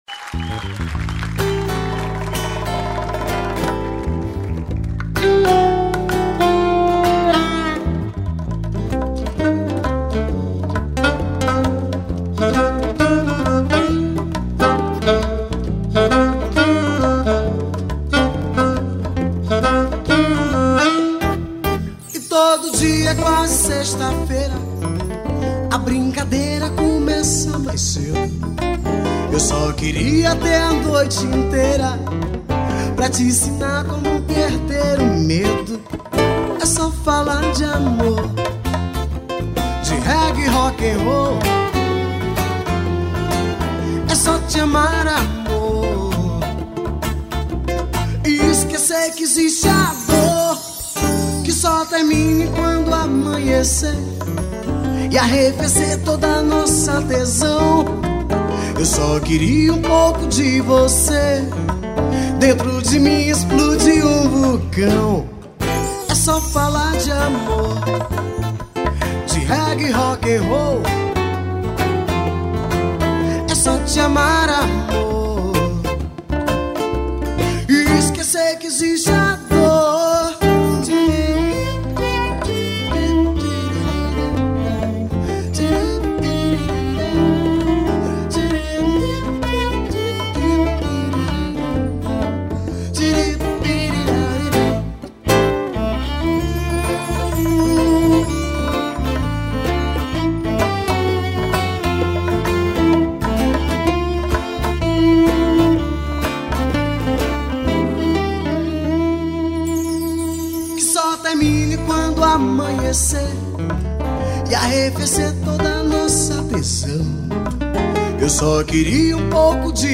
1196   02:38:00   Faixa:     Rock Nacional